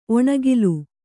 ♪ oṇaggilu